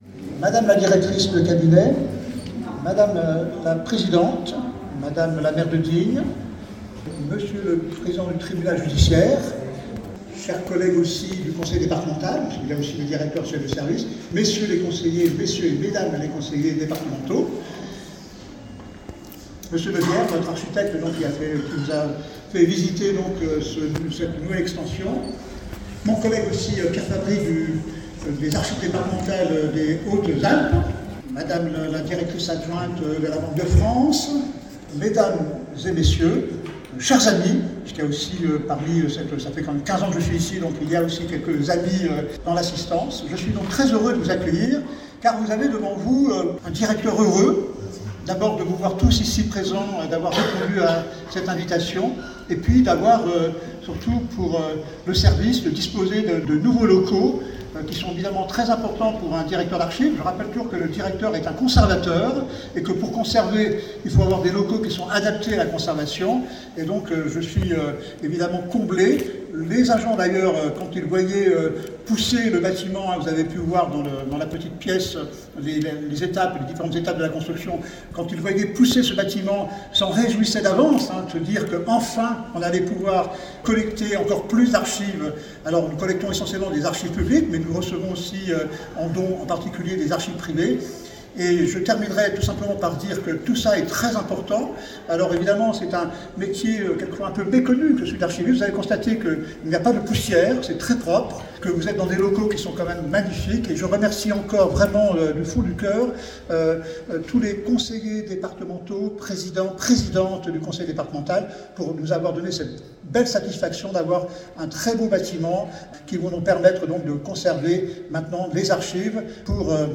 Discours